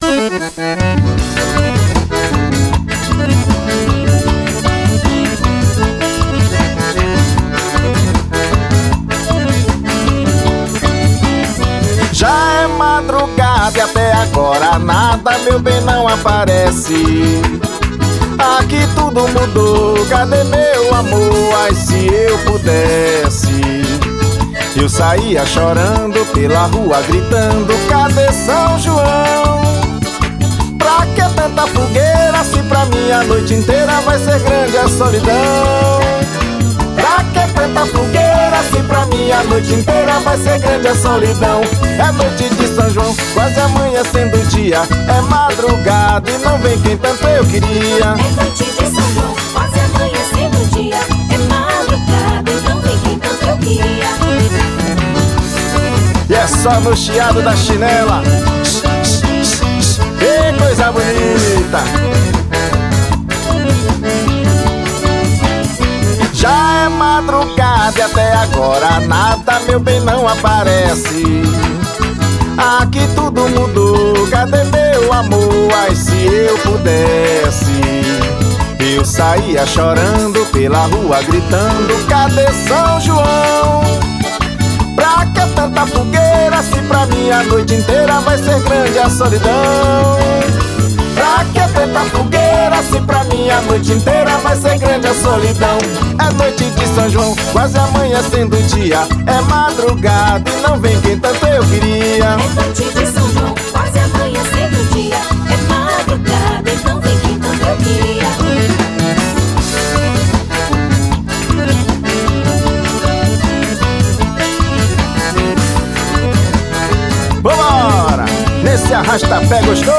1 Baião e 2 xote.